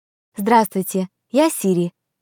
Звуки MacBook Pro и iMac скачать mp3 - Zvukitop
8. Здравствуйте, я Сири (женский голос)